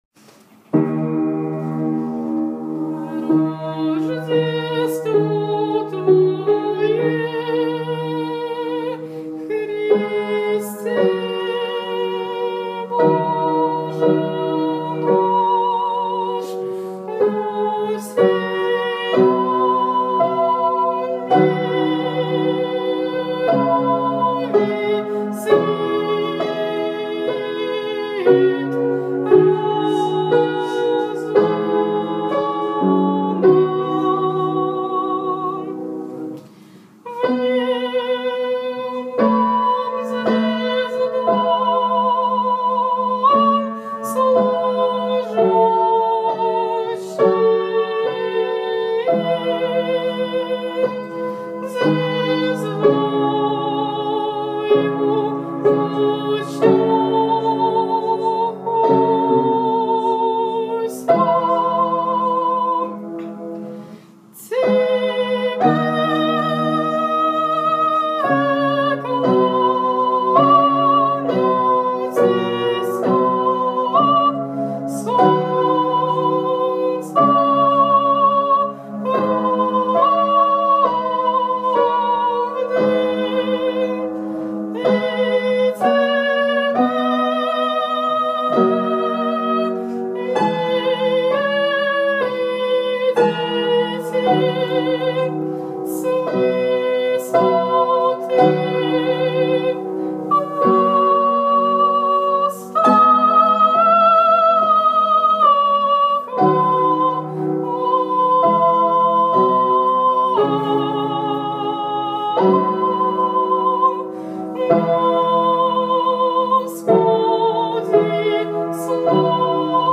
поёт вторым голосом тропарь Рождества Христова
расстроенном пианино.